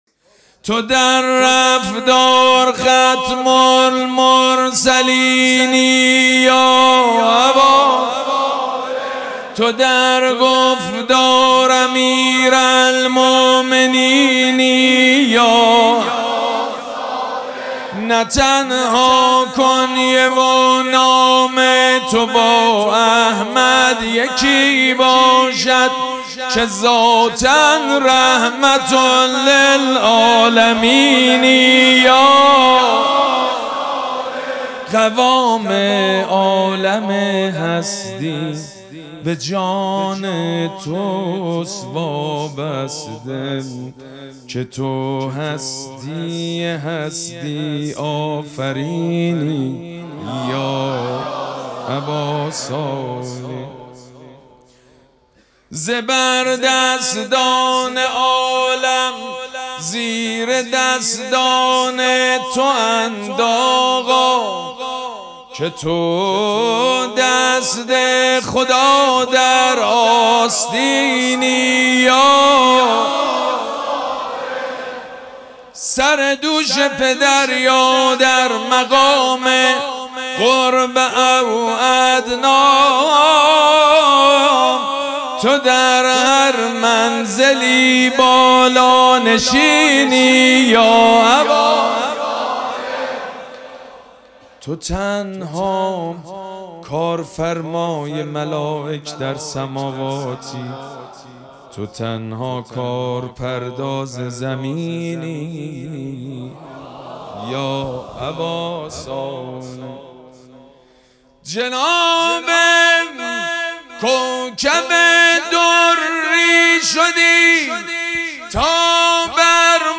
مراسم جشن ولادت حضرت صاحب الزمان (عج)
حسینیه ریحانه الحسین سلام الله علیها
مدح
حاج سید مجید بنی فاطمه